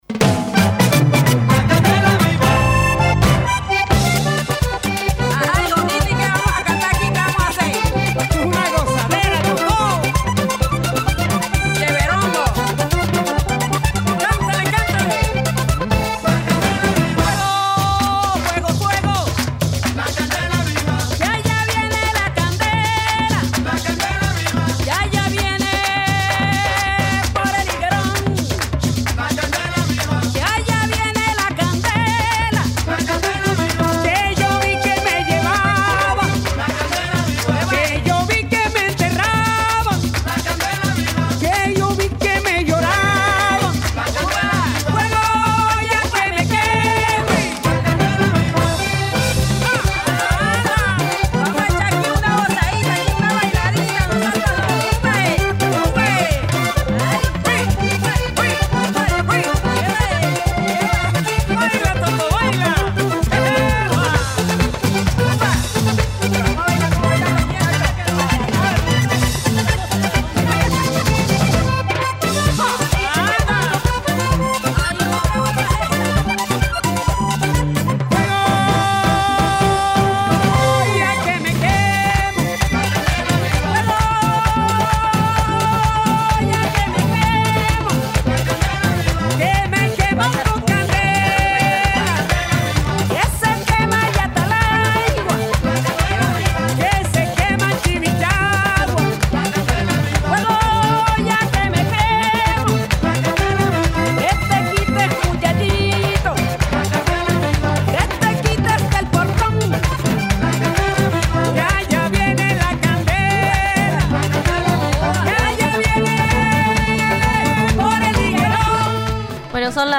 En el nuevo ciclo de la columna de análisis internacional de La Mañana de Uni Radio, hacemos foco en el proceso de paz colombiano, que terminó con 52 años de confrontación.